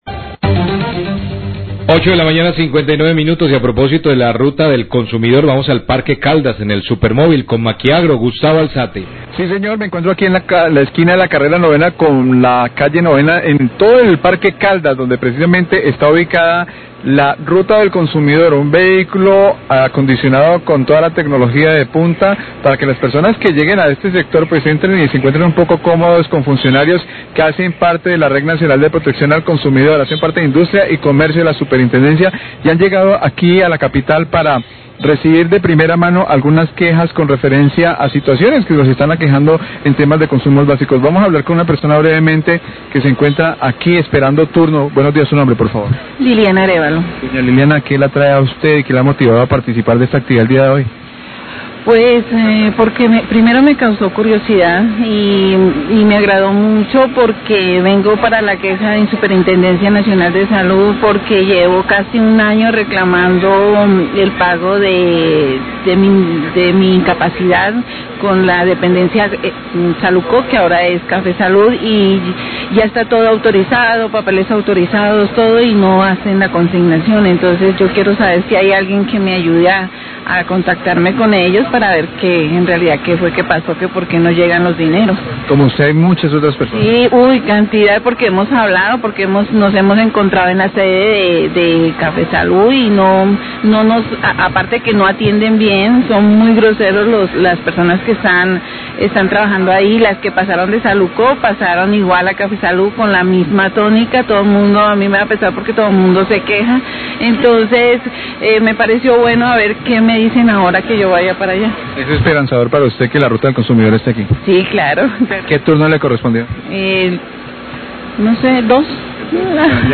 TESTIMONIOS USUARIOS DEL PROGRAMA RUTA DEL CONSUMIDOR
Radio
Usuarios de la jornada de la Ruta del Consumidor, programa de la Superintendencia de Industria y Comercio que visita a Popayán, hablan sobre la atención o diligencias realizadas en esta unidad móvil.